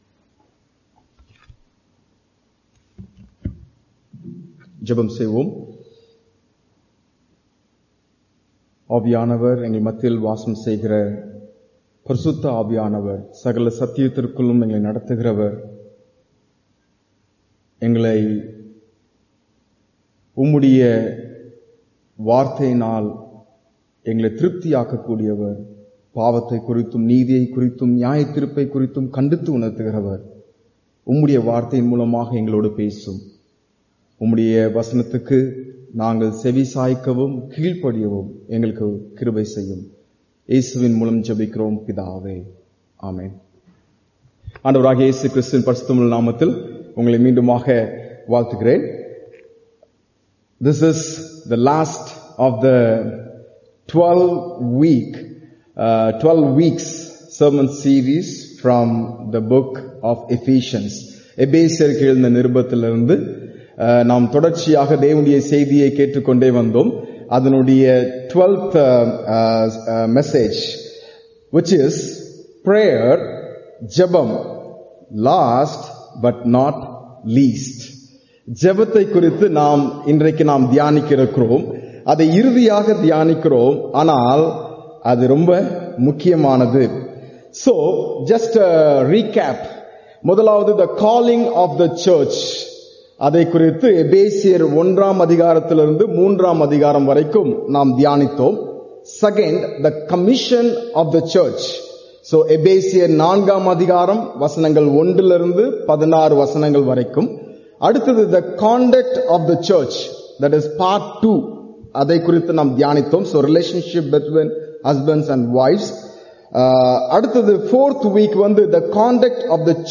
Sermon
Sunday Worship Service